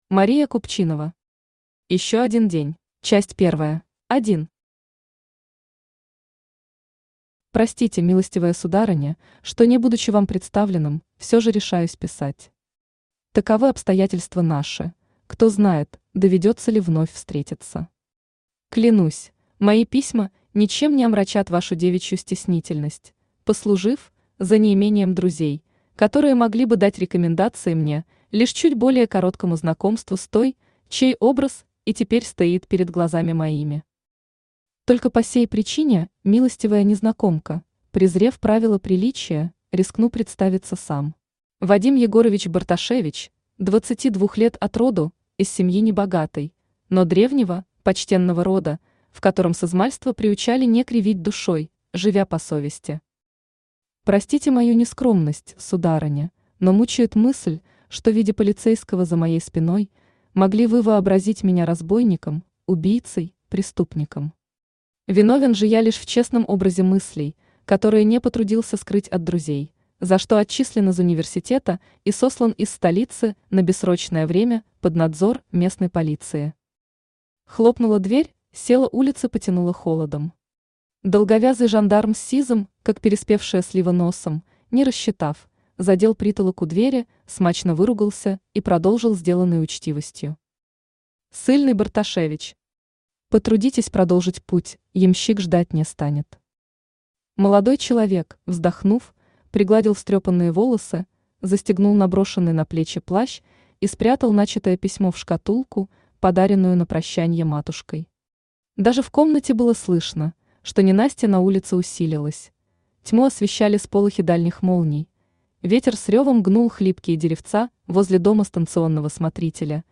Аудиокнига Ещё один день | Библиотека аудиокниг
Aудиокнига Ещё один день Автор Мария Купчинова Читает аудиокнигу Авточтец ЛитРес.